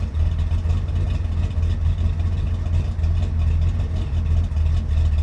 v8_03_idle.wav